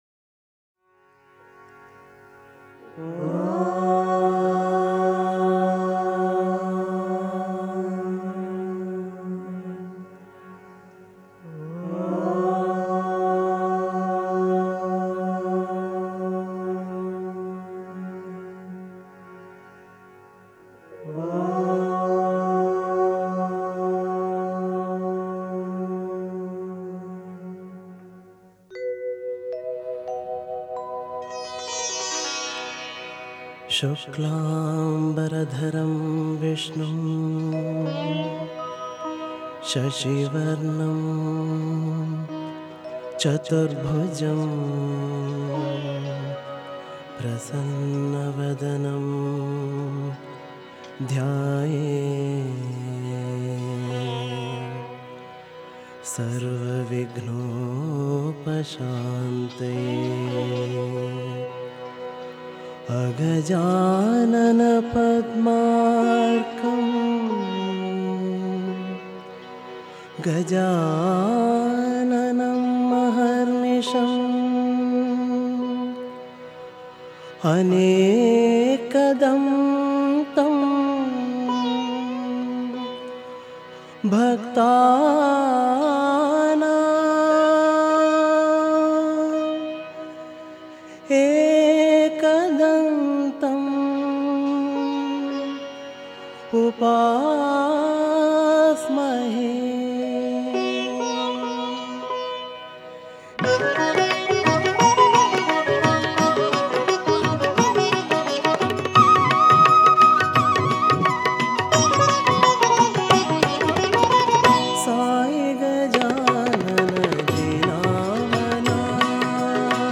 Author adminPosted on Categories Ganesh Bhajans